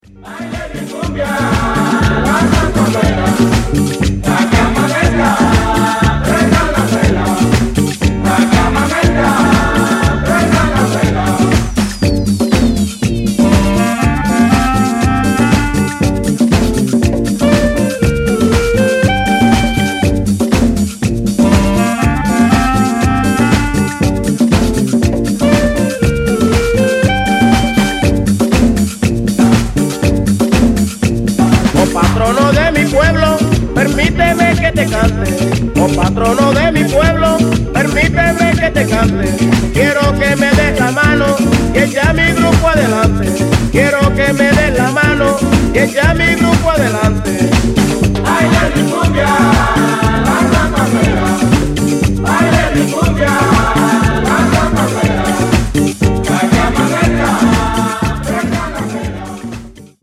a tuff latin workout on the flip